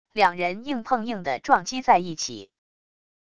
两人硬碰硬的撞击在一起wav音频